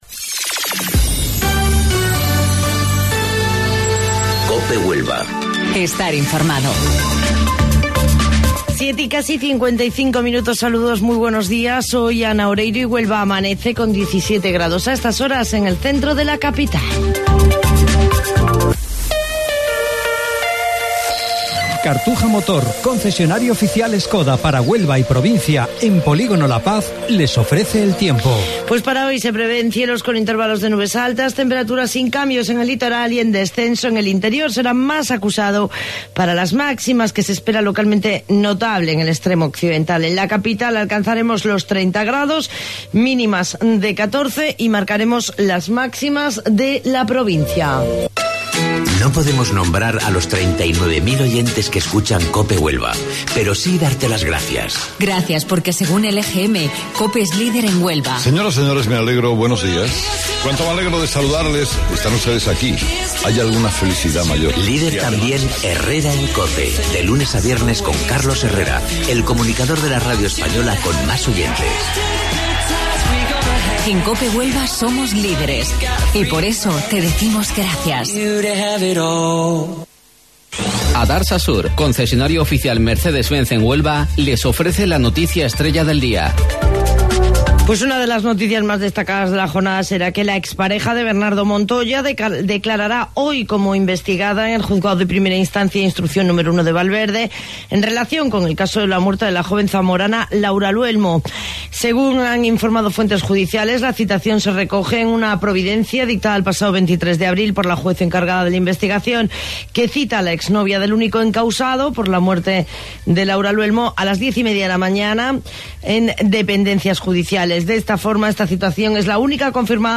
AUDIO: Informativo Local 07:55 del 16 de Mayo